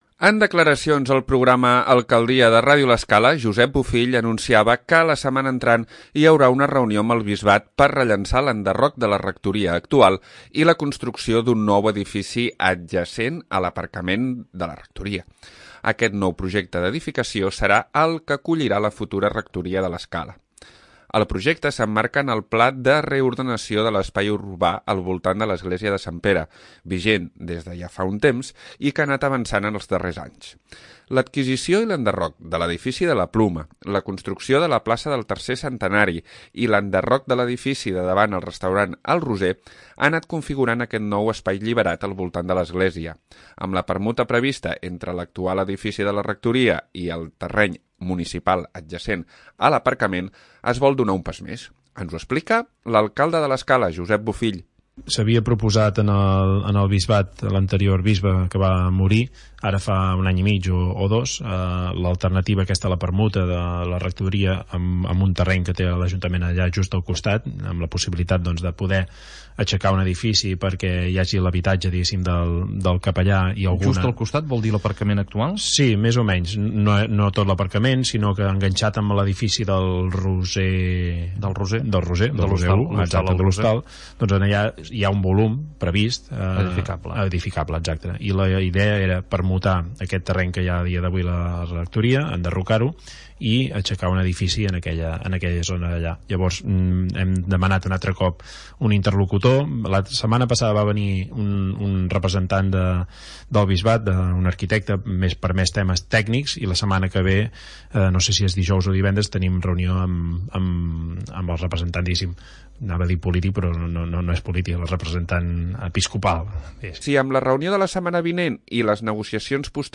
Ens ho explica l'Alcalde de l'Escala Josep Bofill.